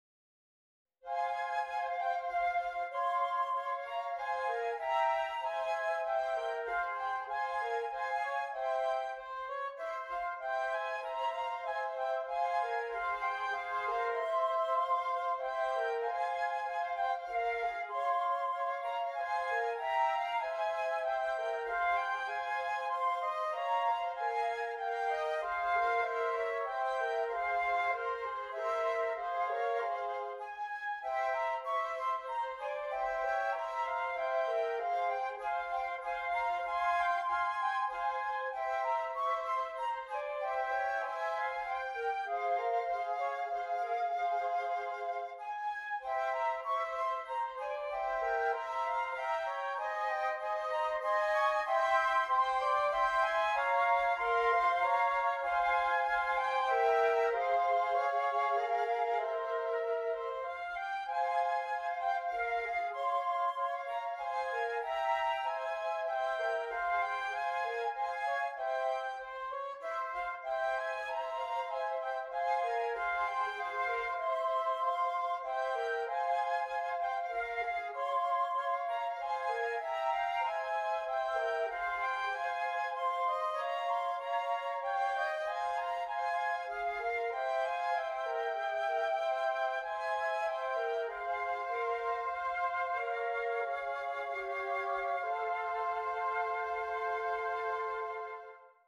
3 Flutes
introspective, melancholy work